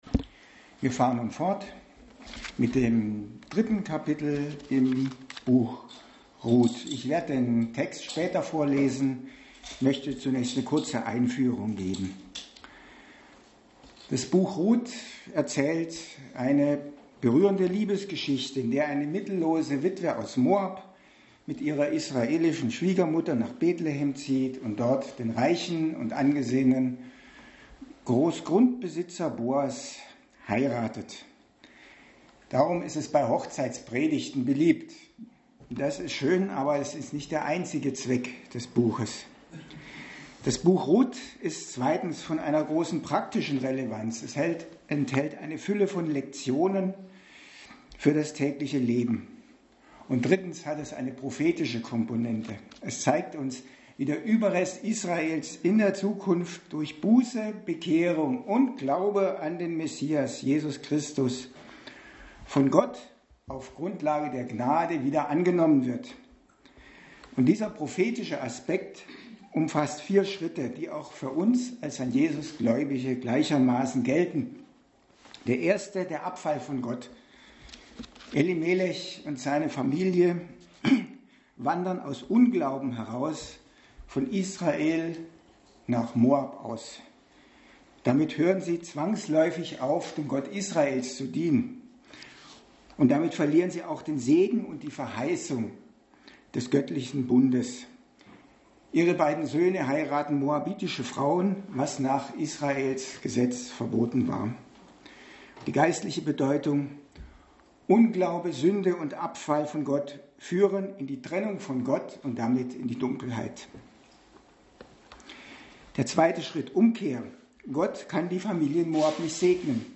Passage: Ruth 3 Dienstart: Predigt